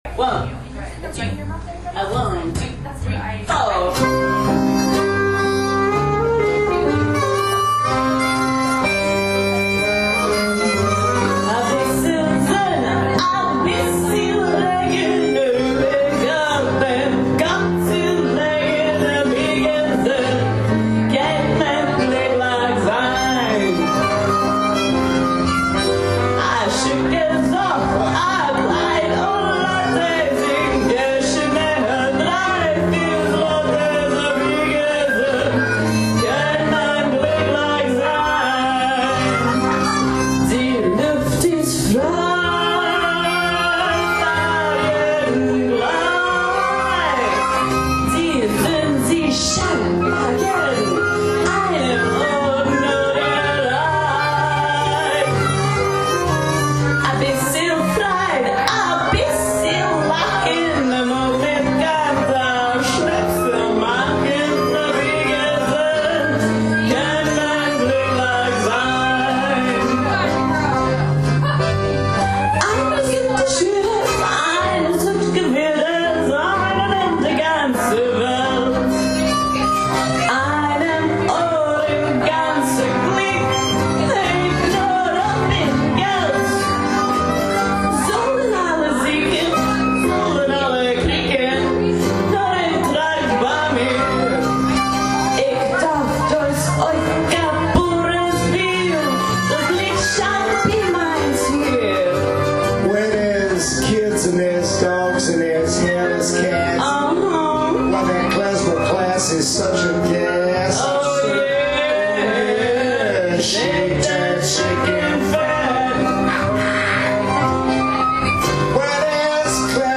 The traditional lyrics are sung